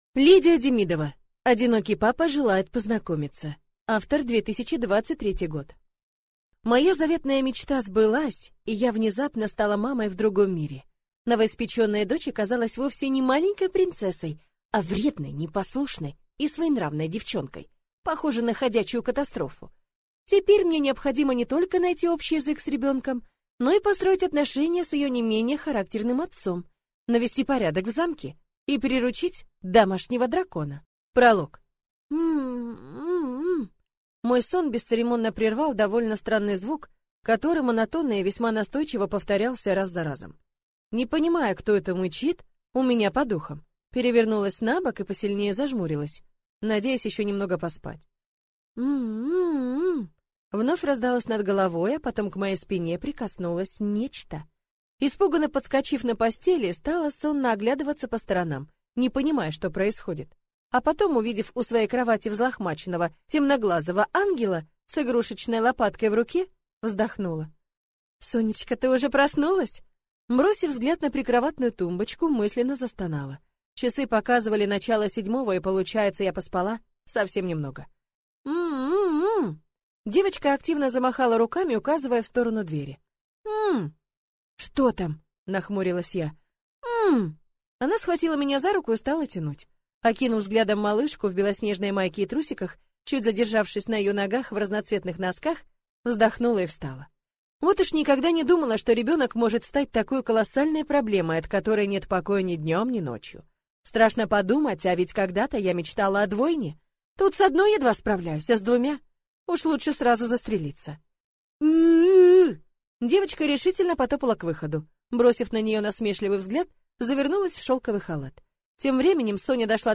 Аудиокнига Одинокий папа желает познакомиться | Библиотека аудиокниг